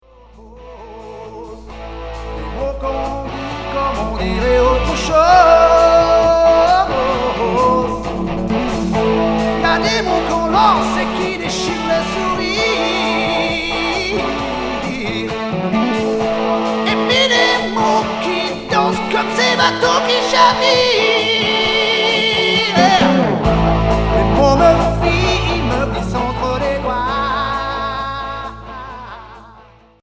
Hard FM Unique 45t retour à l'accueil